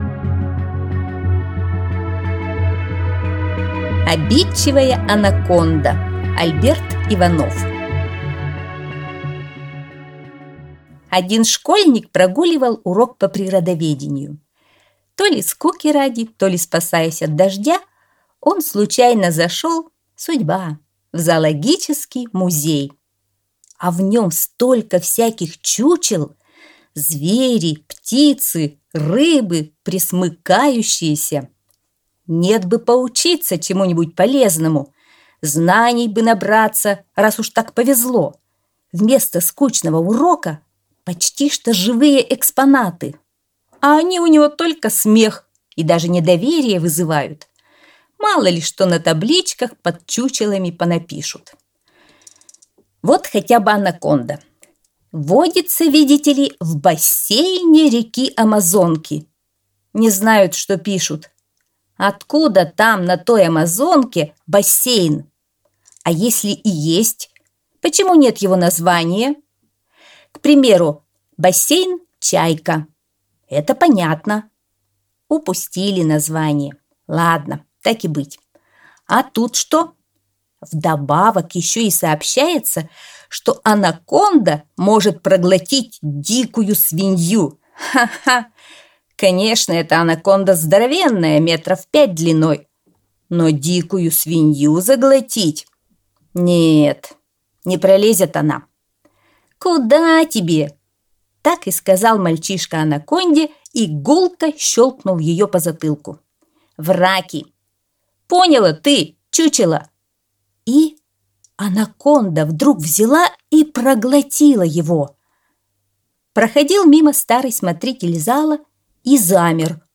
Аудиосказка «Обидчивая анаконда»